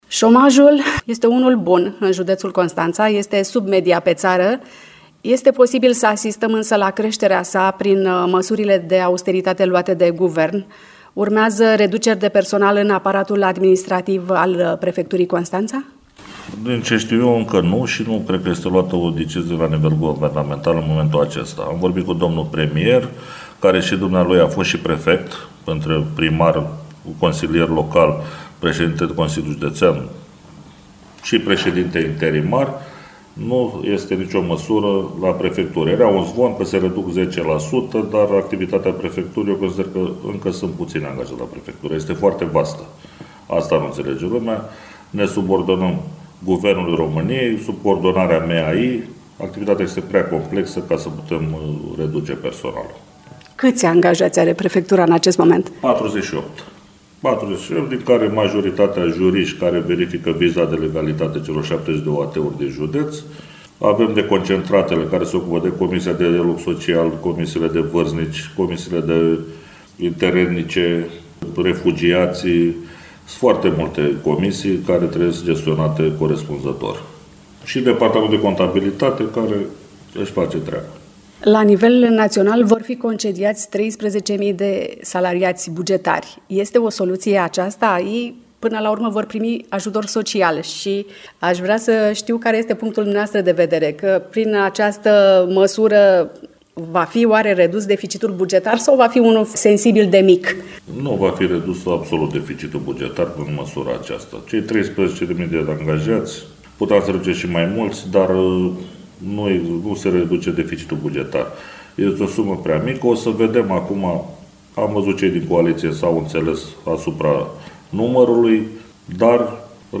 În emisiunea „Dialoguri la zi”